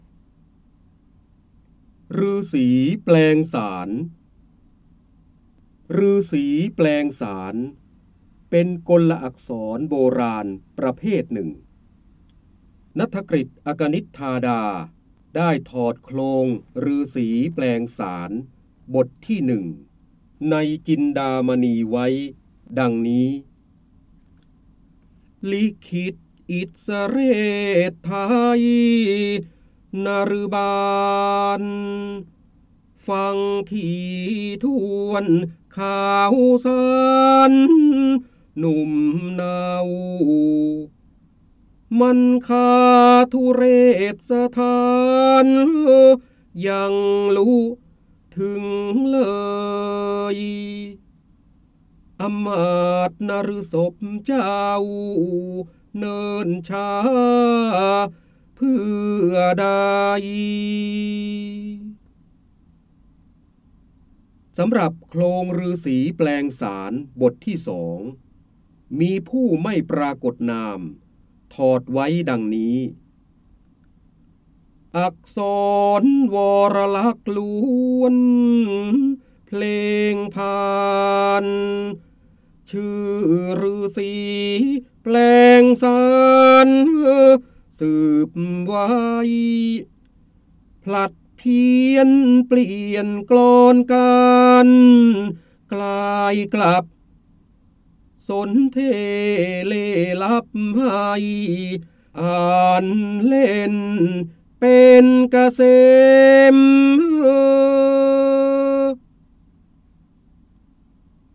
เสียงบรรยายจากหนังสือ จินดามณี (พระโหราธิบดี) ฤาษีแปลงสาร